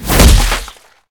kick2.ogg